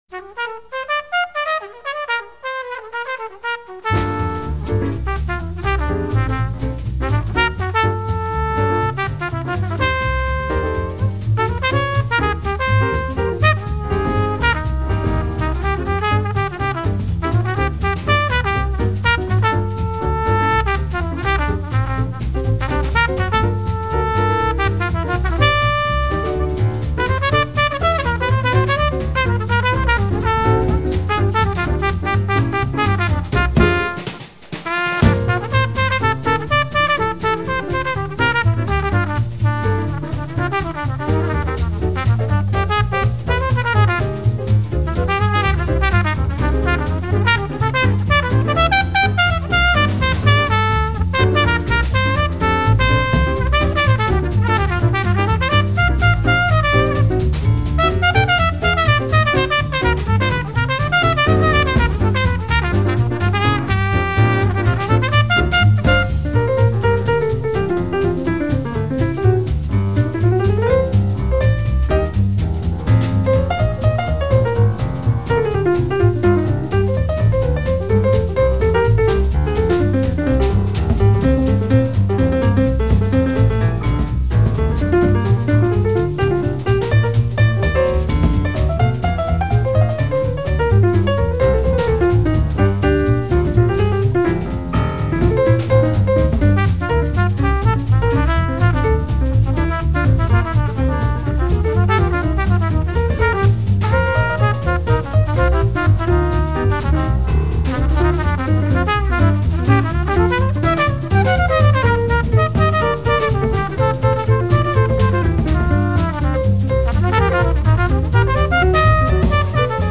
jazz
Trumpet